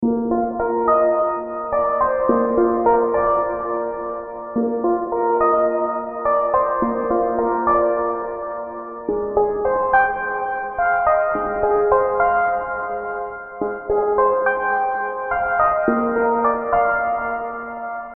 Atmosphere Music Loop
Analog-synthesizer-piano-music-loop-106-bpm.mp3